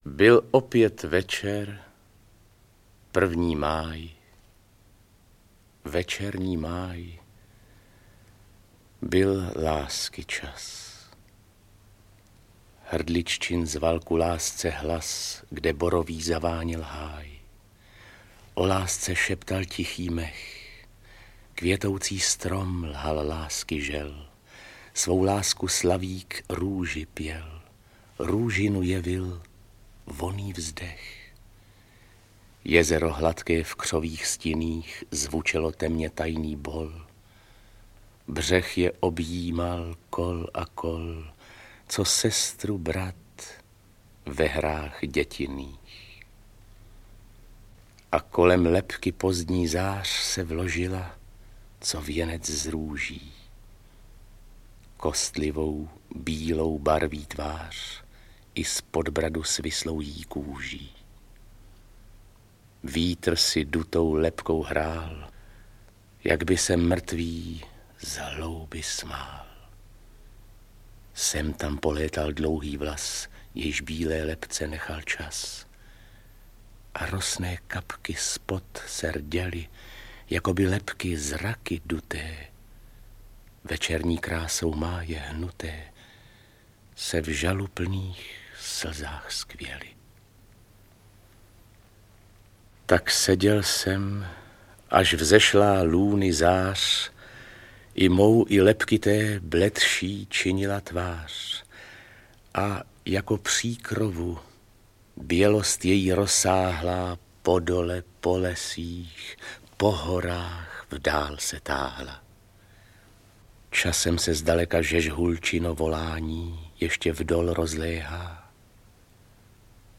Česká poezie pro Základní devítileté školy - druhý výběr 1. část audiokniha
Ukázka z knihy